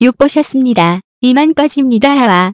그래서 전문 성우의 목소리로 문장을 읽어주는 TTS 소프트웨어를 찾아봤는데, 마침 250자까지는 공짜(?)로 되는 착한 곳이 있더군요~
따라서 위의 TTS 프로그램으로 소리를 내면서 동시에 GoldWave로 녹음을 하면 되는 것입니다.